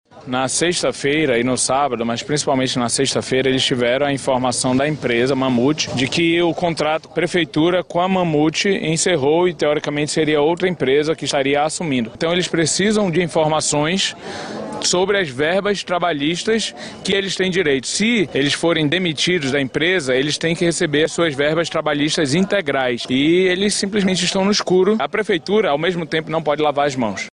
O vereador Rodrigo Guedes do Republicanos acompanhou a manifestação, e destacou que mesmo que a empresa privada seja a responsável pelos colaboradores desligados, a Prefeitura de Manaus deve intervir e tomar as medidas necessárias.
SONORA_MANIFESTACAO-GARIS.mp3